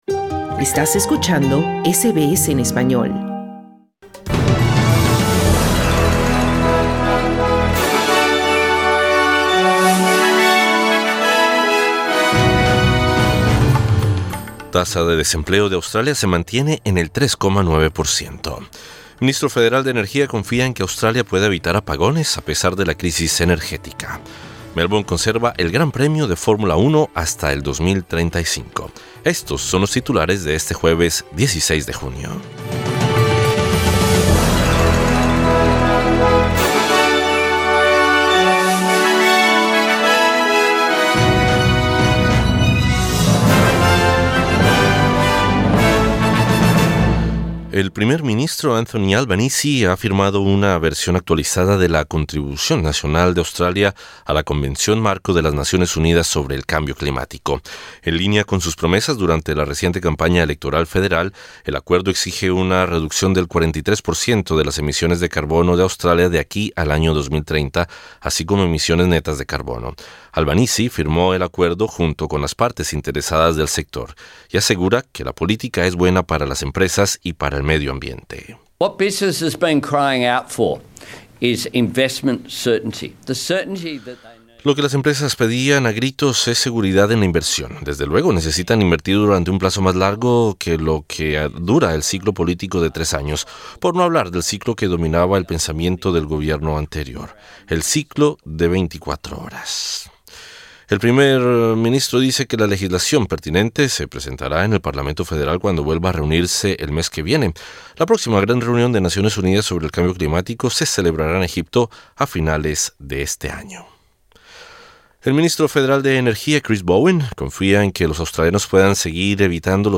Noticias SBS Spanish | 16 junio 2022